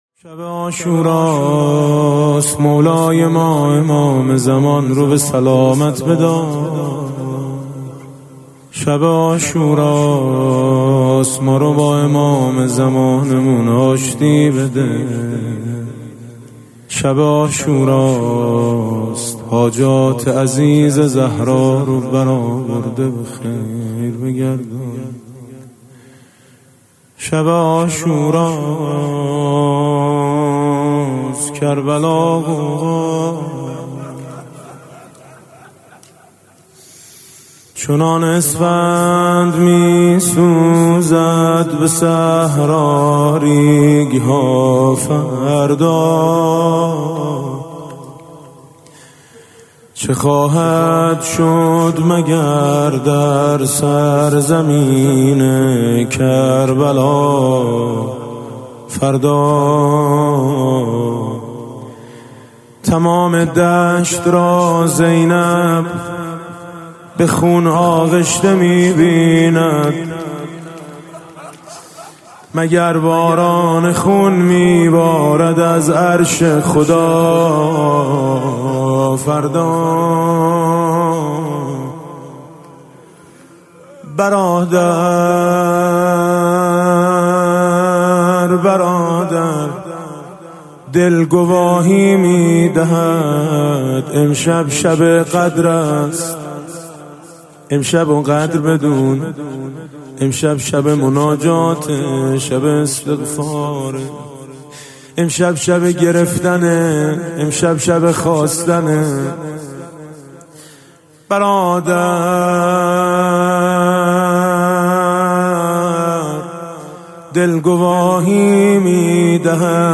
حضرت زینب (س) در شب عاشورا نگران حضرت ابا عبدالله الحسین (ع) است. حاج میثم مطیعی مداح اهل بیت (ع) در اين مقتل خوانی و روضه خوانی از نگرانی‌های خواهر می‌گوید.